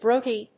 /ˈbroti(米国英語), ˈbrəʊti:(英国英語)/